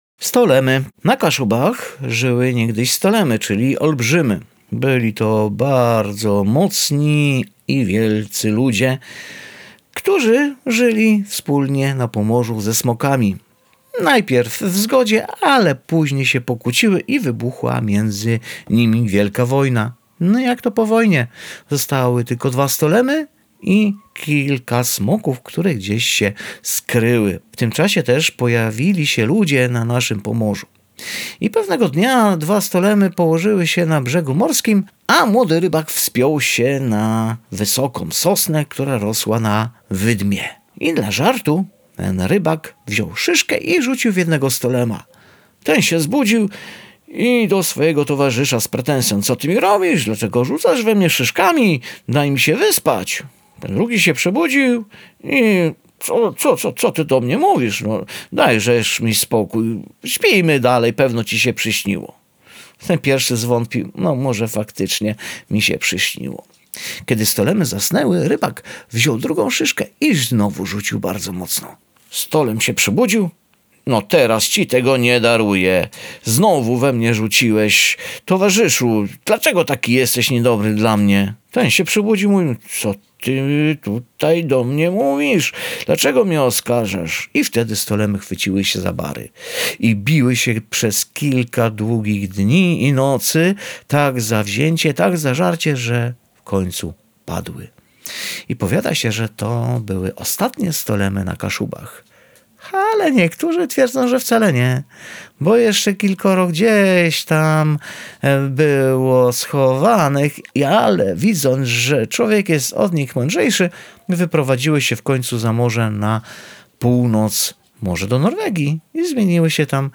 Bajka „O stolemach”.